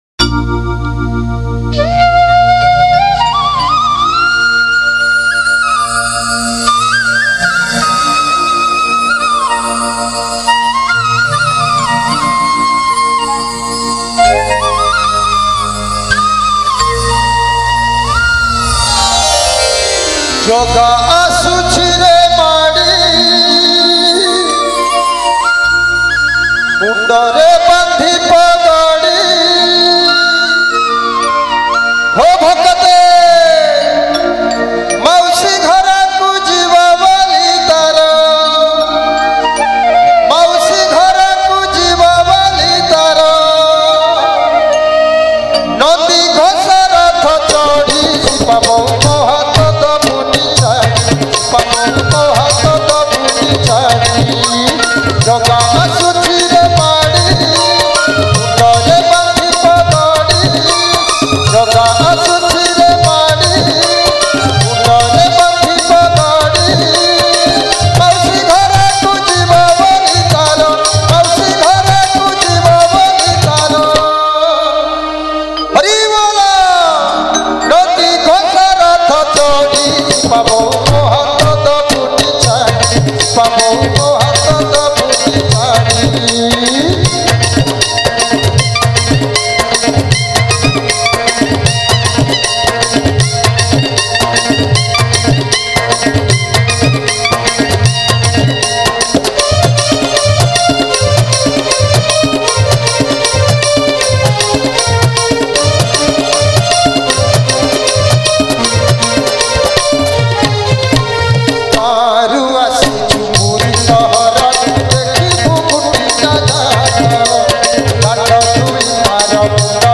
Keyboard
Rhythm & Drums Programming